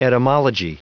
Prononciation du mot etymology en anglais (fichier audio)
Prononciation du mot : etymology